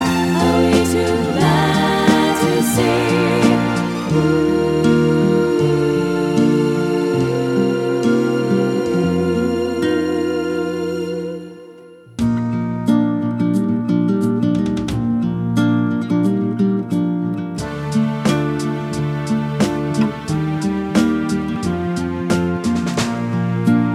Minus Guitars Pop (1960s) 2:53 Buy £1.50